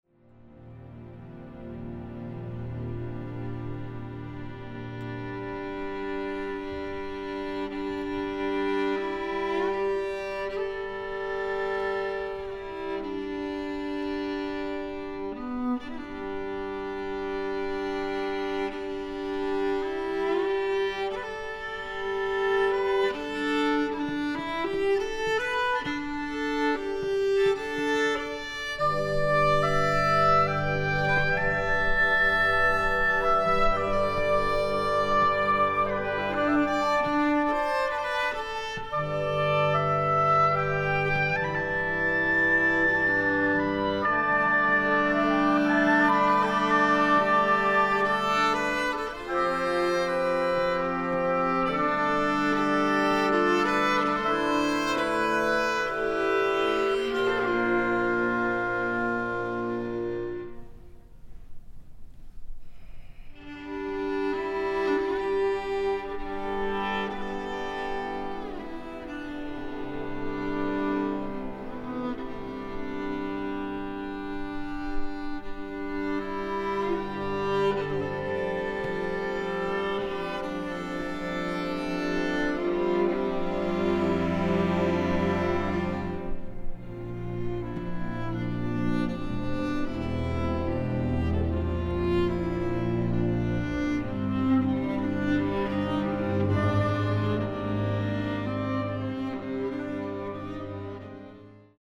viola & chamber orchestra
(2222 2 hrn 2 tpt 2 prc strings)